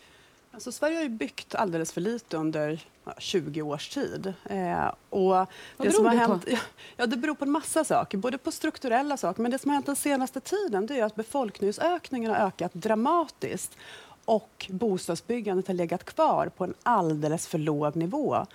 Så här sa hon i gårdagens Debatt.